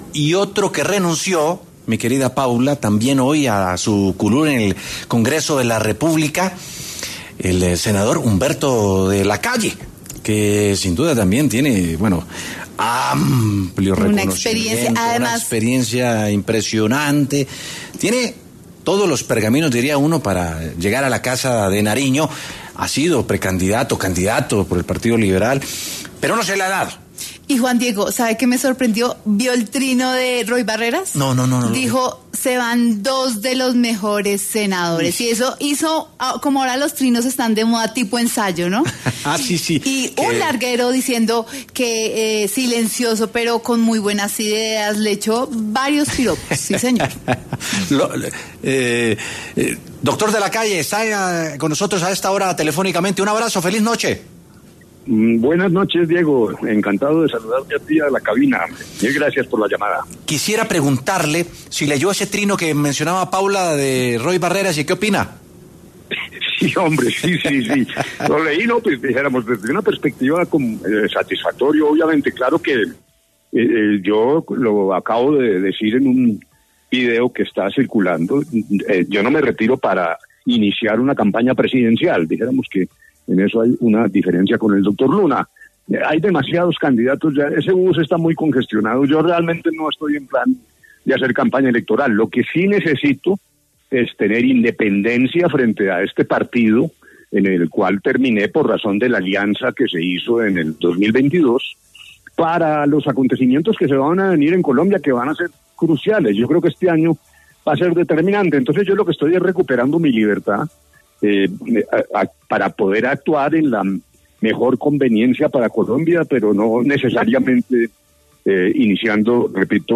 Humberto de la Calle conversó en W Sin Carreta sobre su decisión se retirarse del Senado y de su Partido Verde Oxígeno, de cara a las elecciones de 2026.
El ahora exsenador pasó por los micrófonos de W Sin Carreta para hablar sobre esta drástica decisión en un periodo donde se empiezan a caldear los ánimos electorales de cara al 2026.